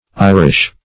Irish \I"rish\, a. [AS.
Irish \I*rish"\, n. sing. & pl.